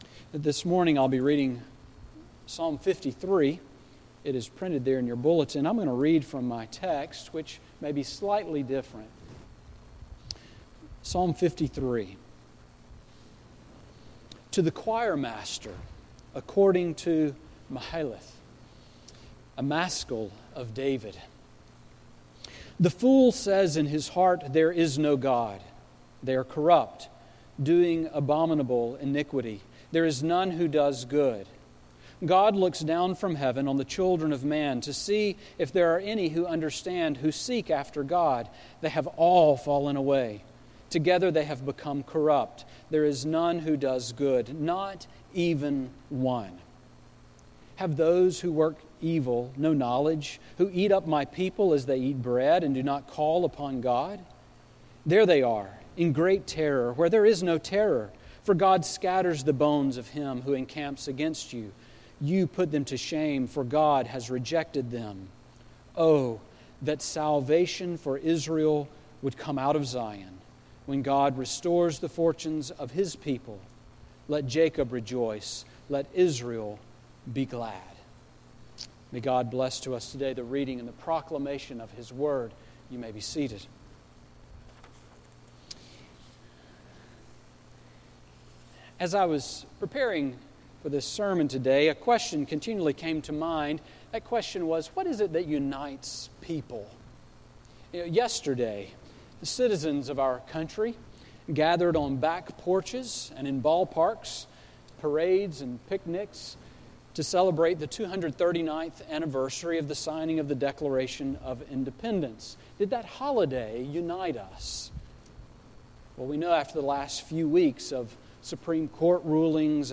Sermon on Psalm 53 from July 5, 2015